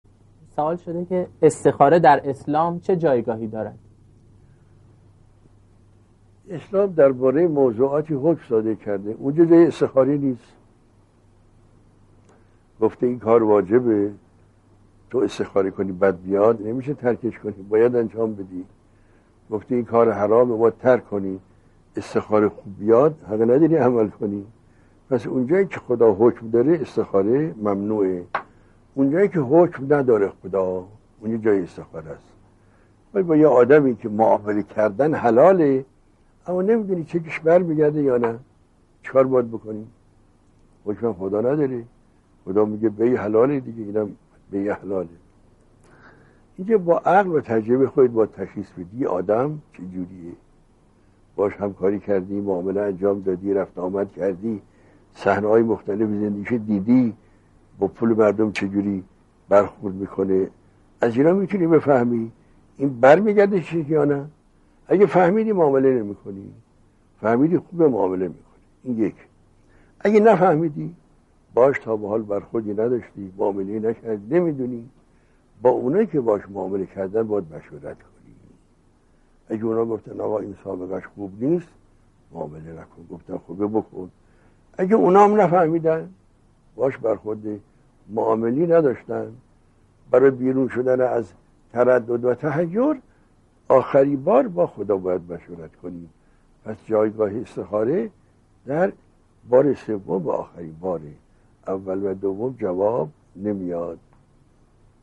در یکی از دروس اخلاق خود